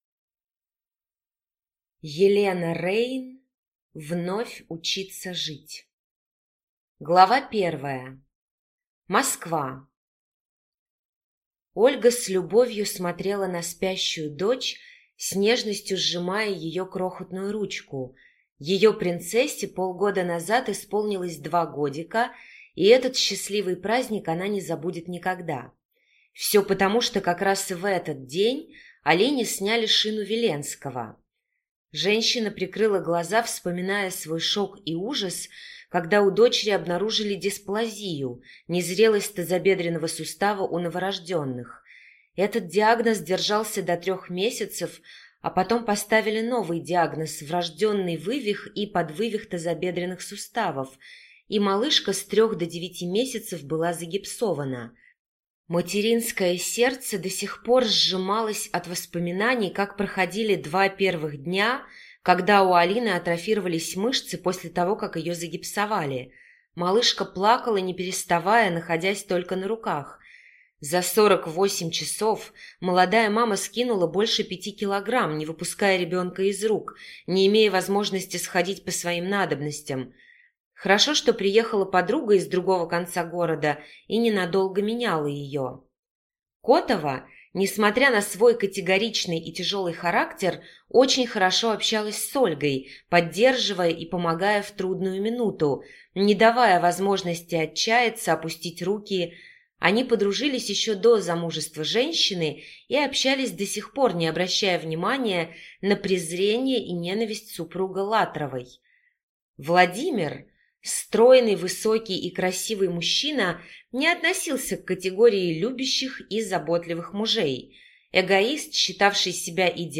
Аудиокнига Вновь учиться жить | Библиотека аудиокниг